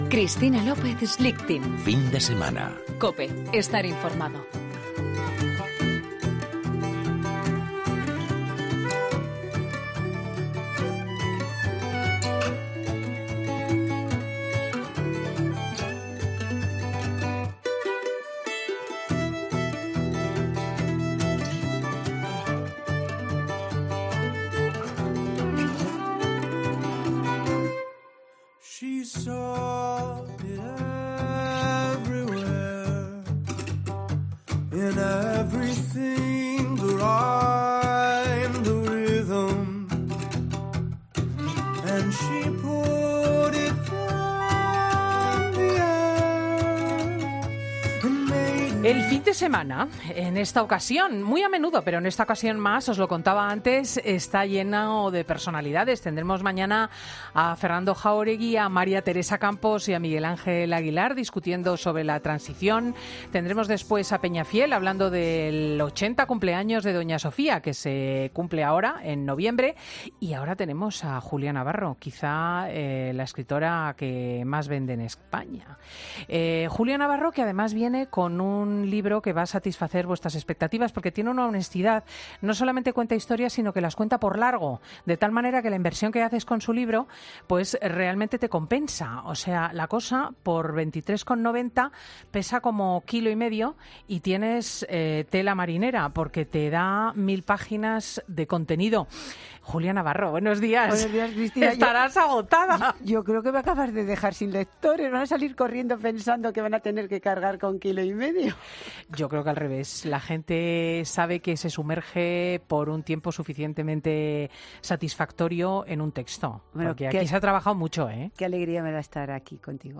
En el programa Fin de Semana COPE con Cristina López Schlichting, la veterana periodista ha presentado su nueva novela "Tú no matarás", un título que consigue, con tan solo tres palabras, atrapar al lector en una especie de red que le lleva a introducirse en esta nueva historia de culpa, venganza y peso de la conciencia.